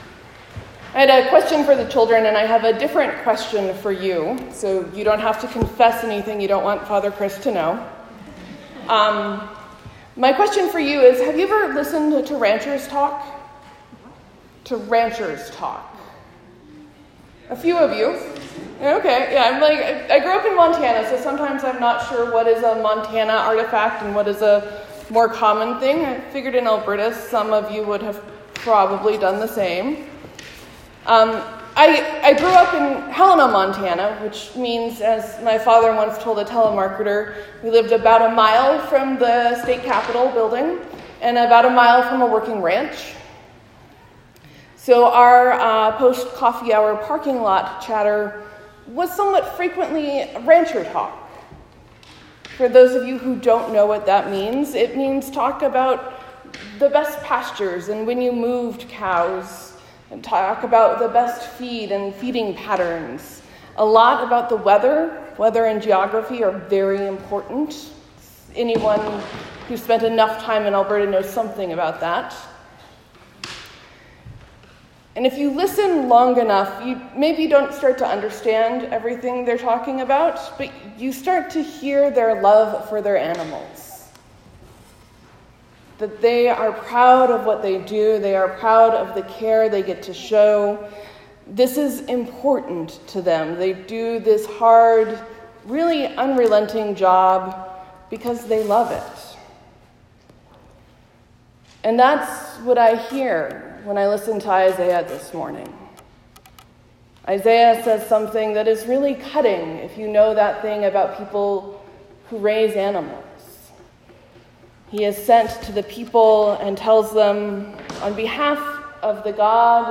I was back in the pulpit today!